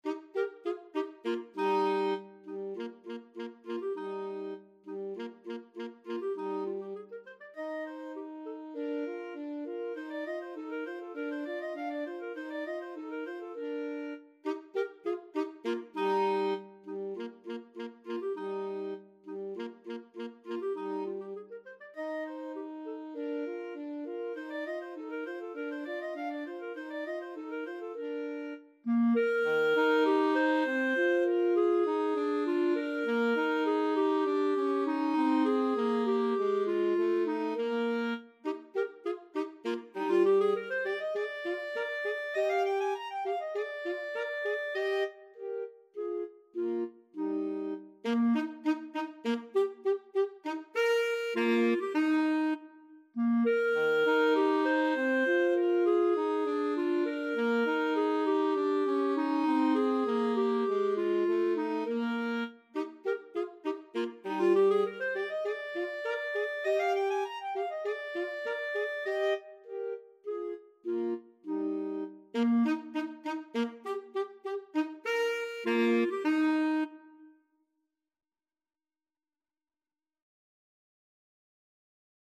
ClarinetAlto Saxophone
2/4 (View more 2/4 Music)